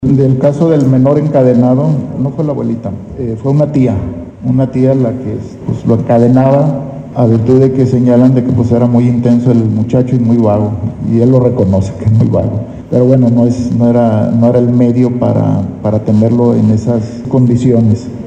Así lo afirmó el fiscal De jalisco, Salvador González de Los Santos.